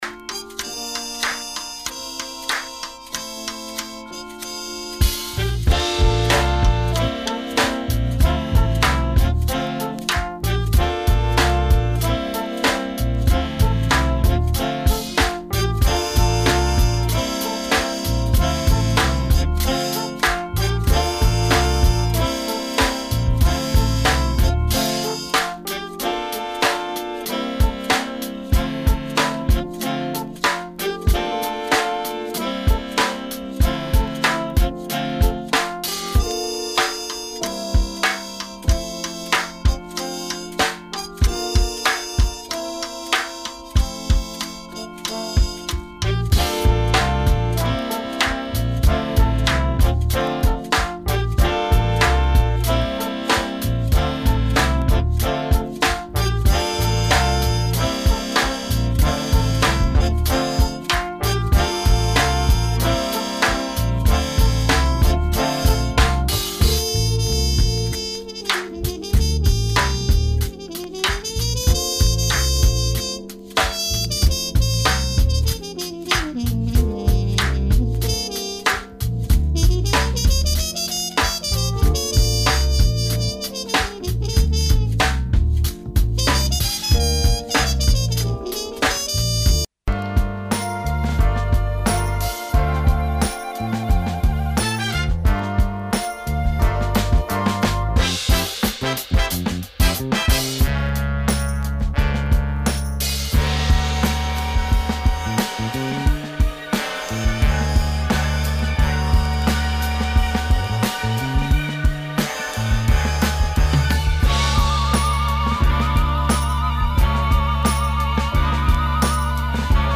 ⌂ > Vinyly > Hiphop-Breakbeat >